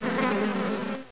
1 channel
beehive.mp3